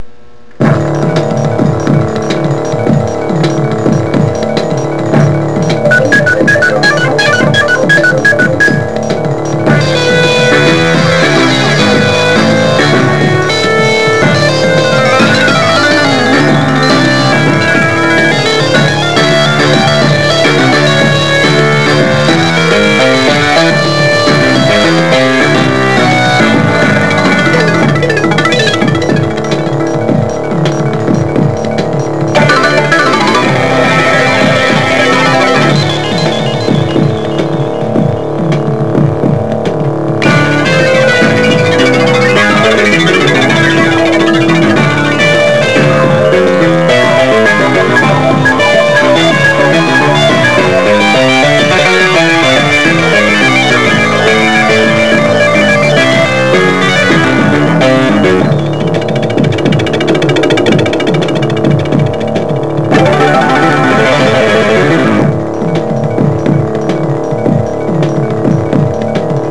Download 753Kb Tema fra spillet